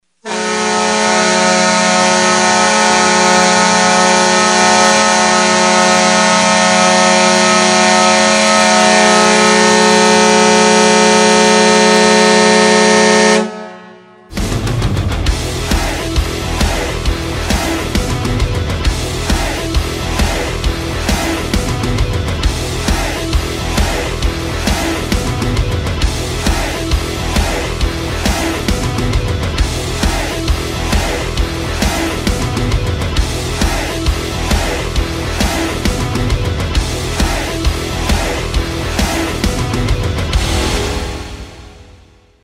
Category: Sports   Right: Personal
Tags: Princeton Hockey Baker Rink